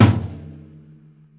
描述：使用Will Vinton的录音室鼓组录制的打击乐样本。
Tag: 打击乐器 工作室 汤姆